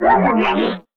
Creature 001.wav